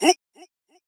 reShout.ogg